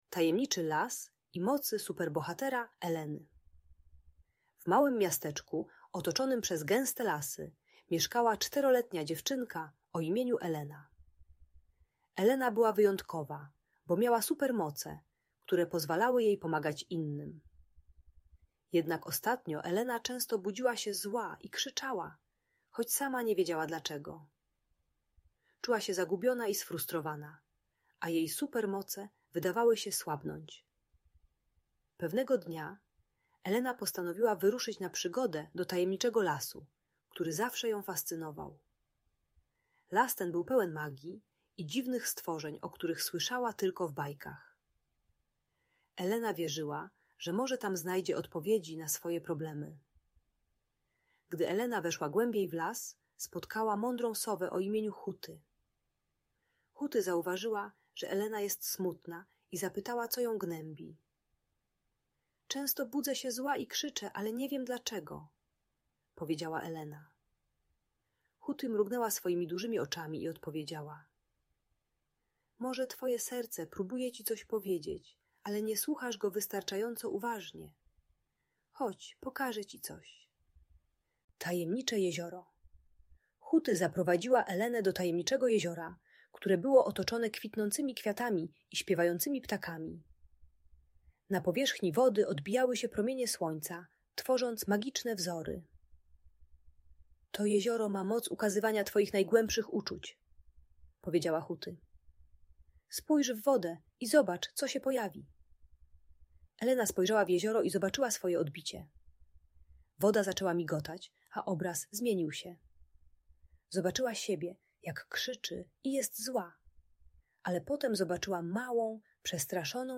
Tajemniczy Las i Mocy Superbohatera Eleny - Opowieść - Audiobajka dla dzieci